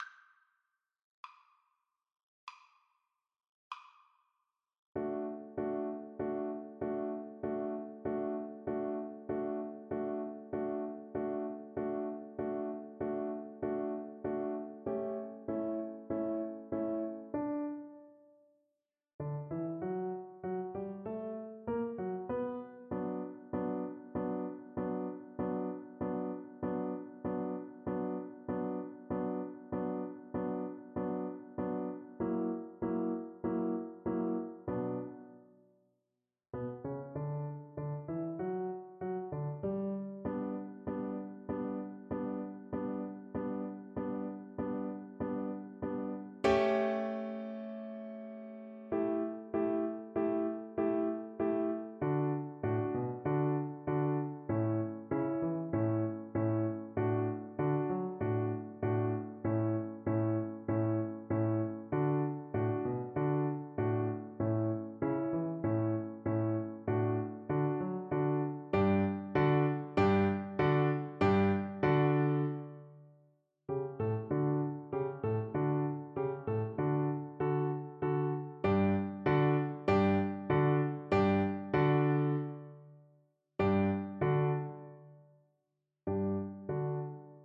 Play (or use space bar on your keyboard) Pause Music Playalong - Piano Accompaniment Playalong Band Accompaniment not yet available transpose reset tempo print settings full screen
ViolinPiano
4/4 (View more 4/4 Music)
= 85 Allegro scherzando (View more music marked Allegro)
Arrangement for Violin and Piano
D major (Sounding Pitch) (View more D major Music for Violin )
Classical (View more Classical Violin Music)